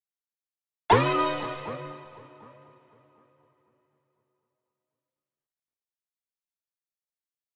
びっくり効果音2
2つ目の効果音は、驚いた時に使われるイメージで制作しております。
あるいは何か閃いた時、天啓が降りてきた時にも使えそうかなと。